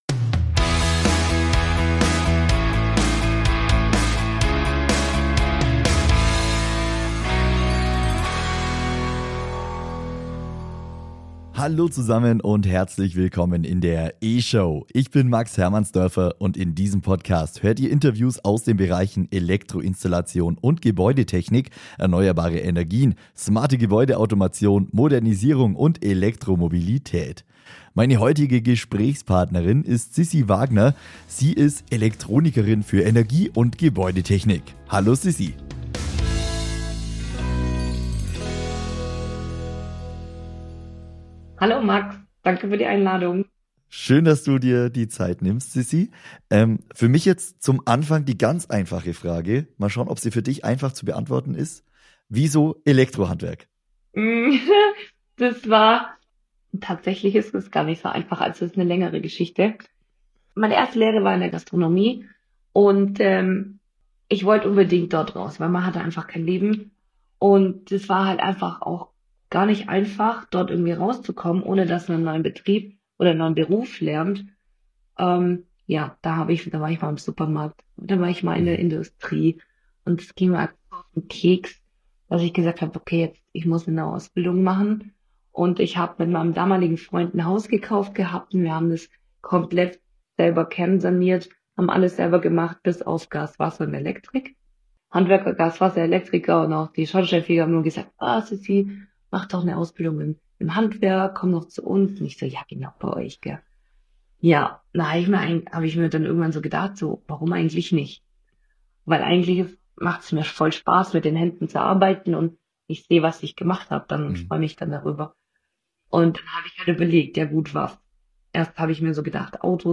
Heute im Interview